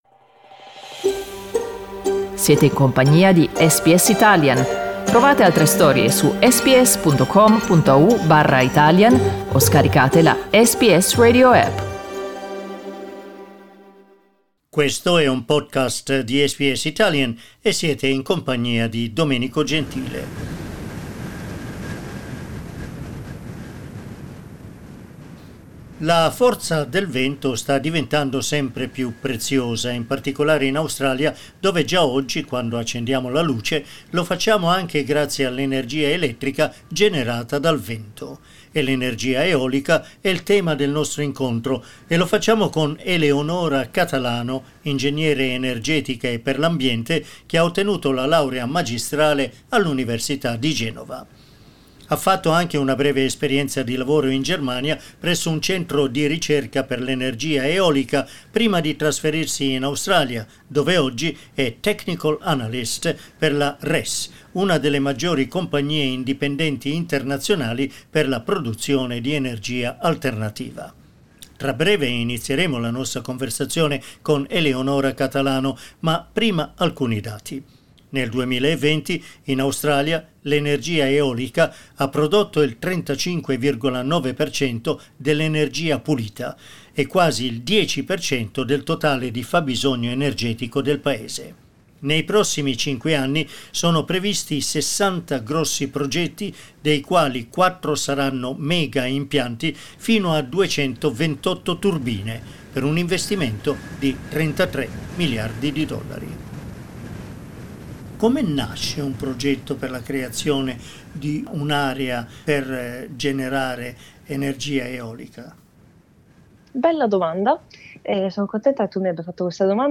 In questa intervista ci racconta la sua esperienza australiana.